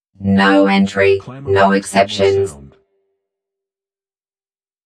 “No entry, no exceptions” Clamor Sound Effect
Can also be used as a car sound and works as a Tesla LockChime sound for the Boombox.